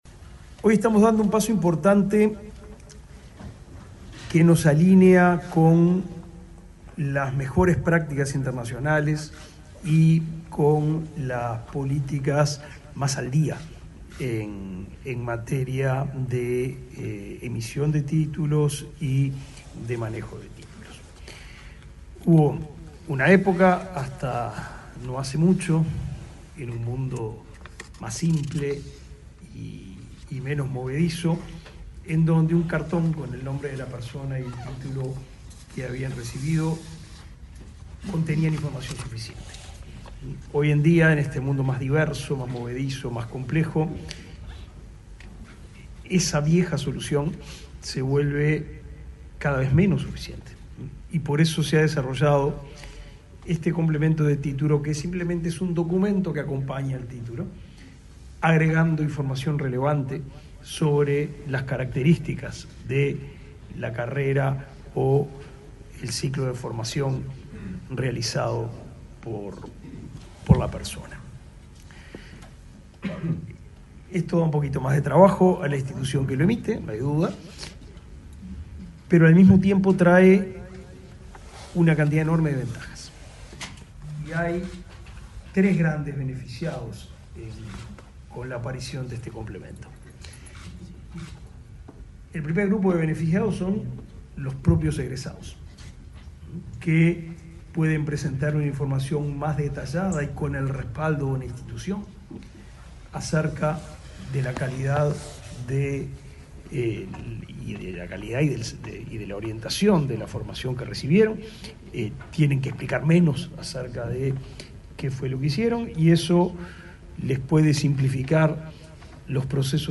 Palabras del ministro de Educación y Cultura, Pablo da Silveira
Este lunes 26 en el Ministerio de Educación y Cultura, el titular de la cartera, Pablo da Silveira, participó en el lanzamiento del suplemento al